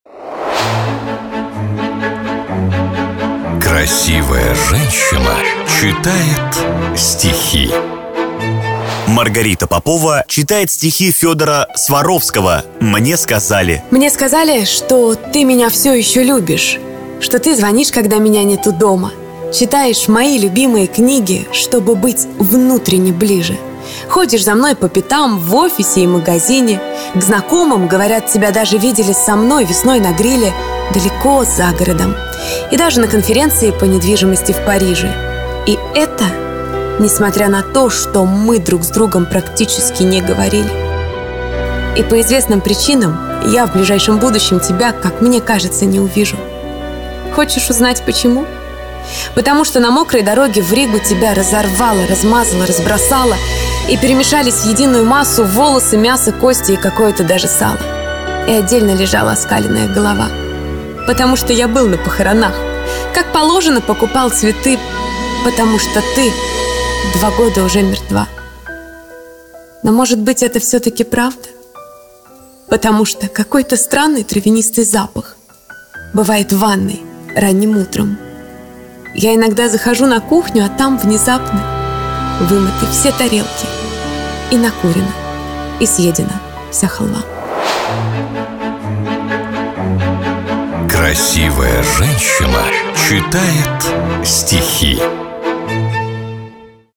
Красивая женщина читает стихи